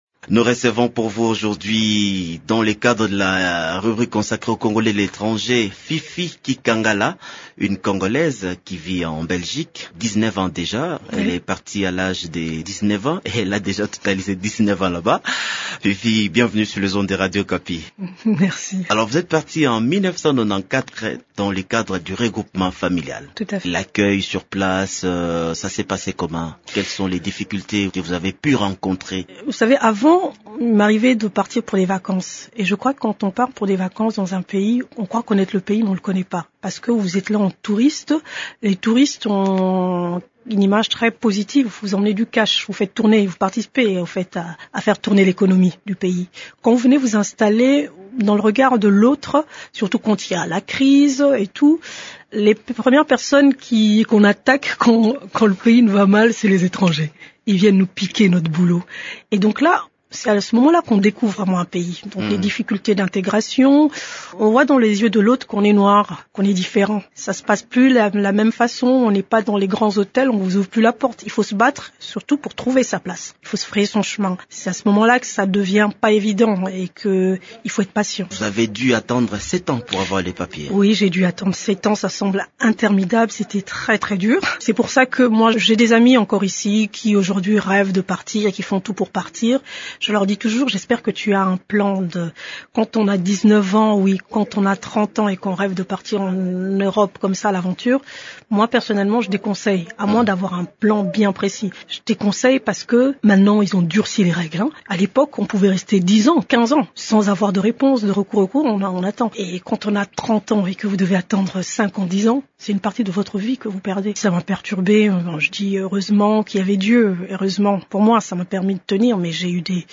dans le studio de Radio Okapi à Kinshasa